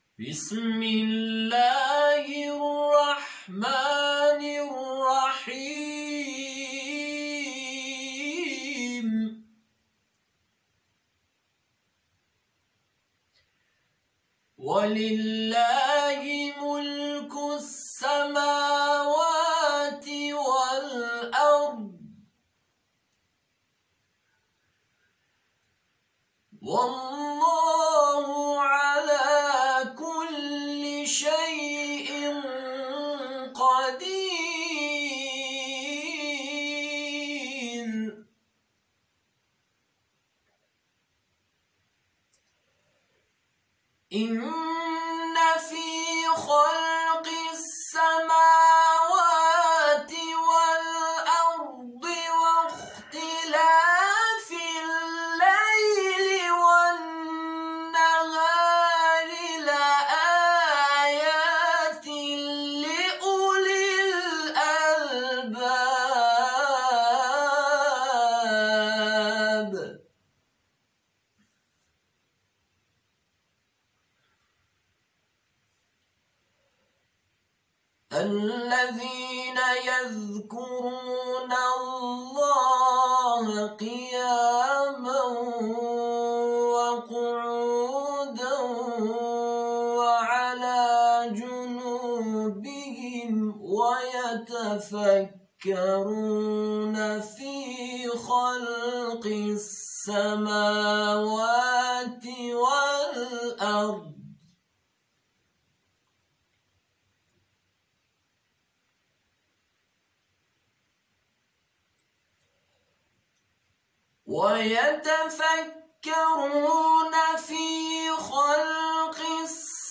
برچسب ها: تلاوت قرآن ، اردبیل